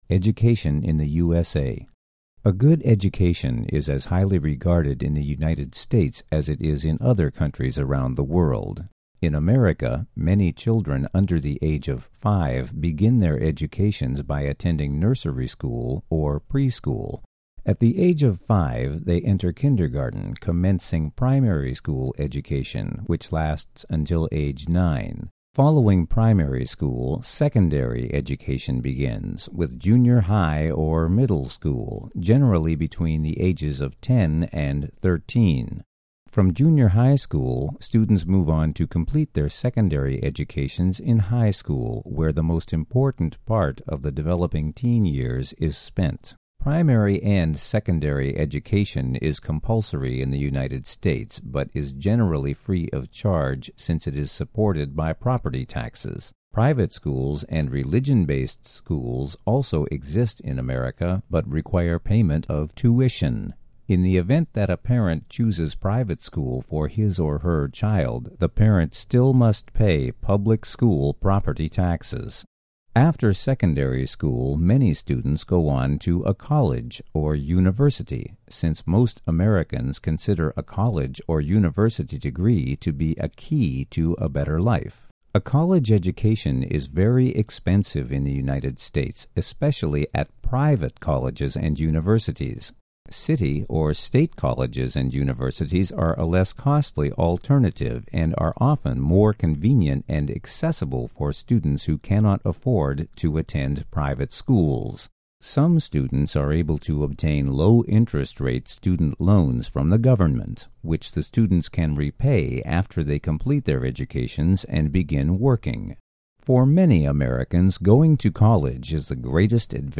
Readings